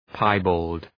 {‘paı,bɔ:ld}
piebald.mp3